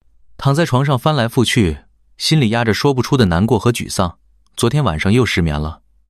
Neutral5.mp3